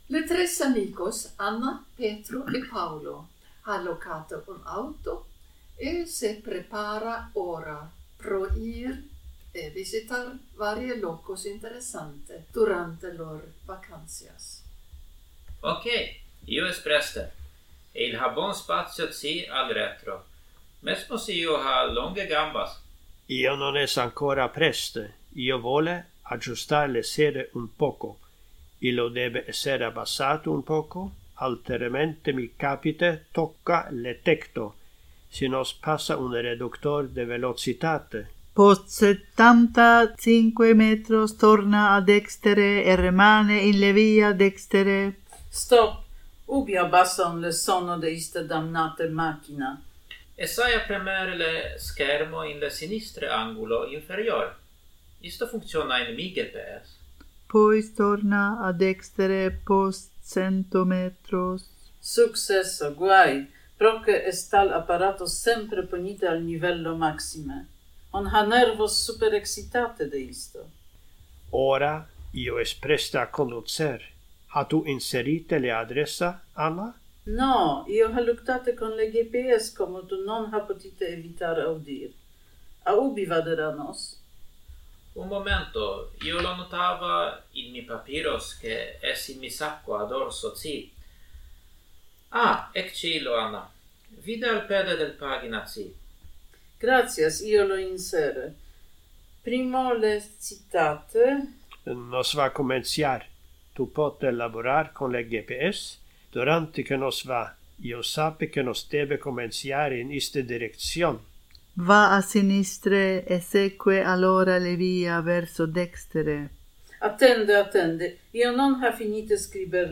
Description: "Viages con interlingua" es un serie de conversationes quotidian con utile phrases e parolas.